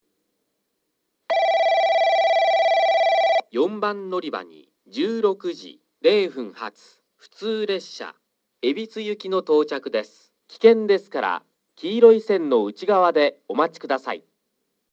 4番のりば接近放送（普通　海老津行き）
放送はJACROS簡易詳細型です。
4番のりばは川崎型から円型ワイドホーンに取り換えられています。